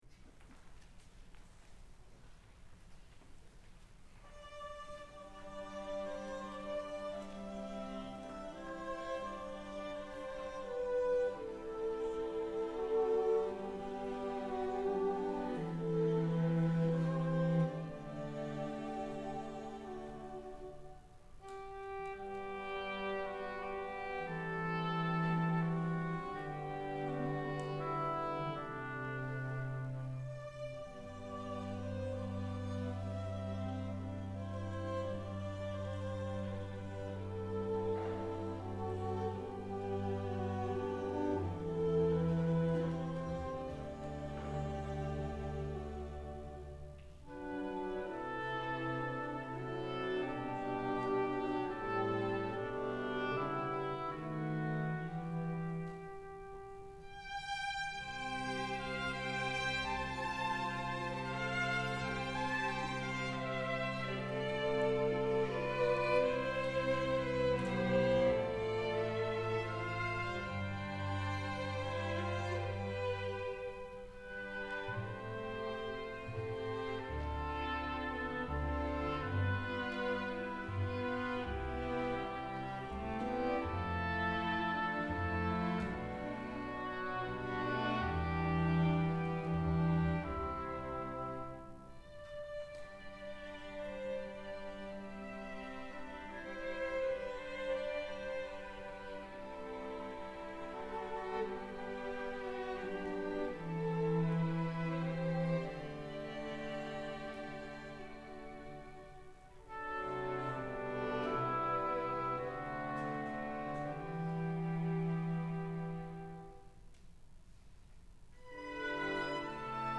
Setting for Oboe and Strings for Christmas or Pentecost